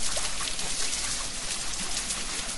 techage_watermill.ogg